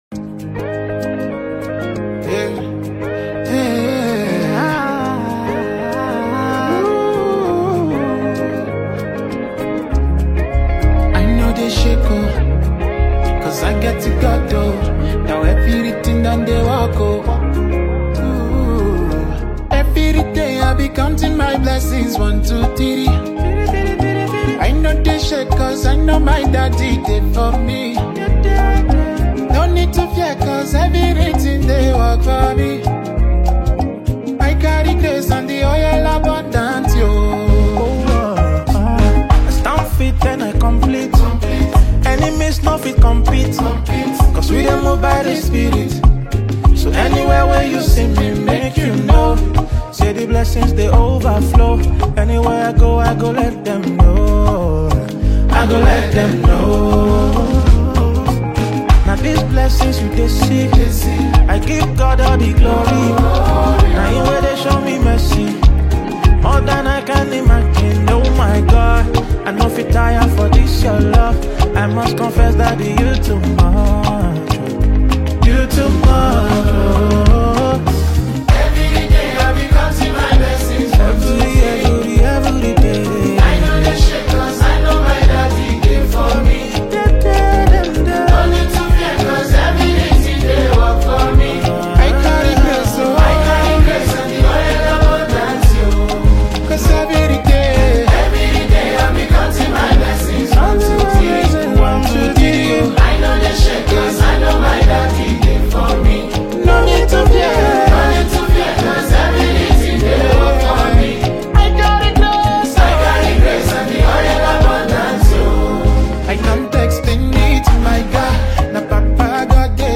uplifting Afro-gospel anthem